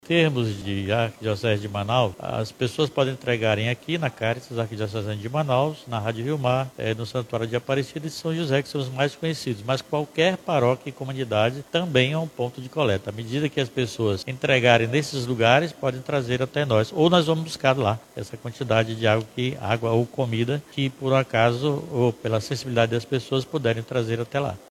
Nessa terça-feira, 26 de novembro, durante coletiva de imprensa na Cúria Metropolitana de Manaus, foi apresentada a campanha humanitária “Água é Vida, Doe Vida”.